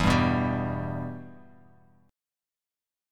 Em Chord
Listen to Em strummed